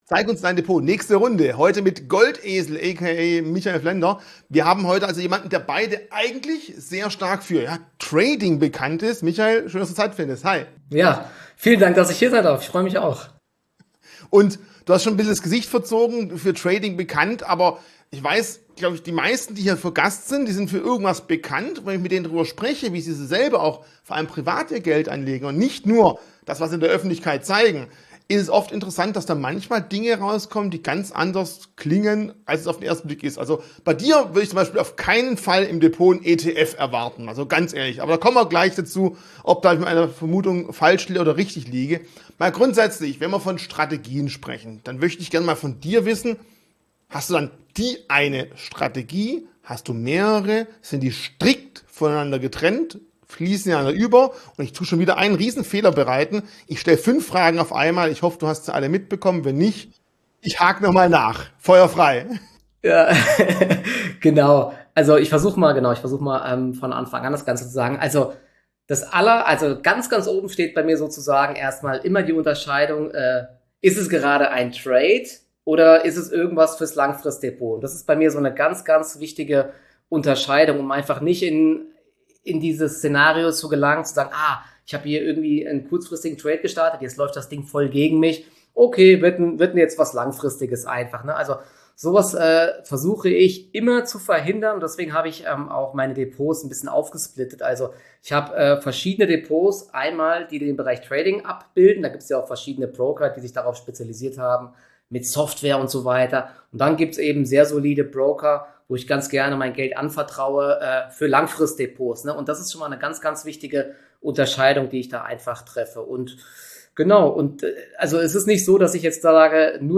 Auf Dich warten Börsenberichte unserer Händler direkt vom Parkett sowie Interviews mit bekannten Experten aus der Branche.